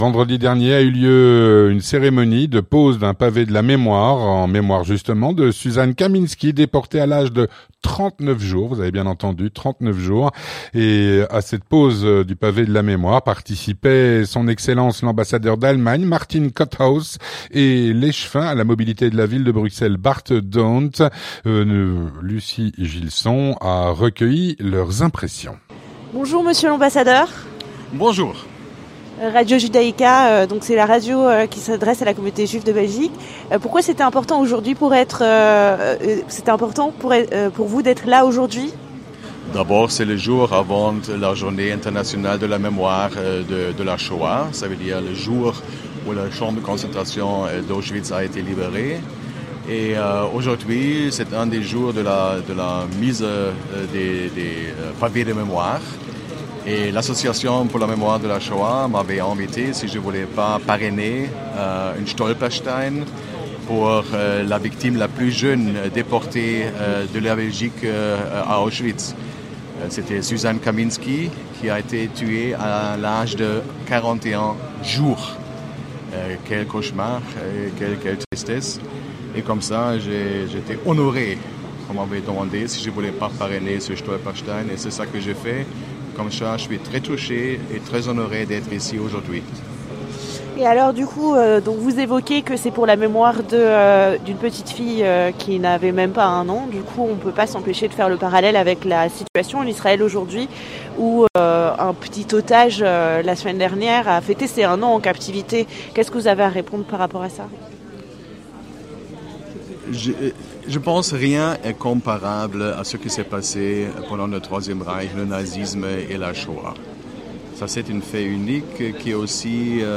Vendredi 26 janvier, avait lieu à Bruxelles une cérémonie de pose de "Pavé la Mémoire" pour Suzanne Kaminski, déportée à l'âge de 39 jours.
Avec S.E. Martin Kotthaus, ambassadeur d’Allemagne en Belgique, et Bart Dhondt, échevin à la mobilité de la Ville de Bruxelles.